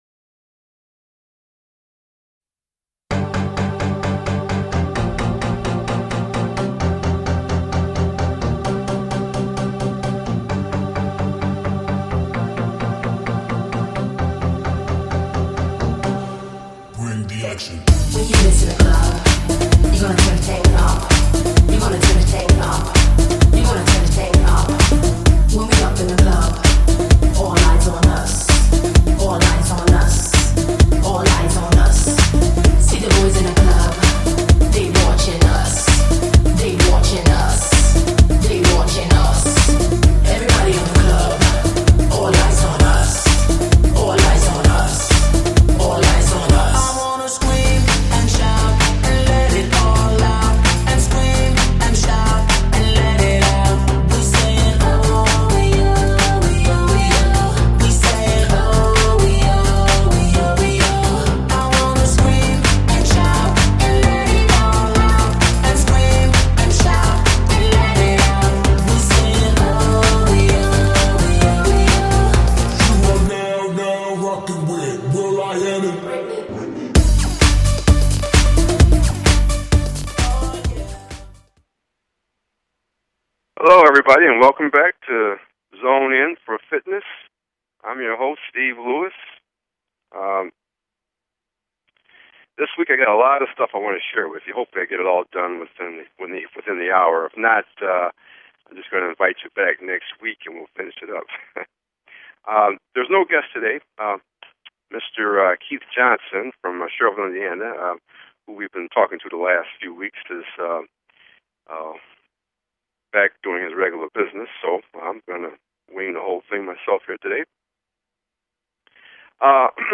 Talk Show Episode, Audio Podcast, Zone In!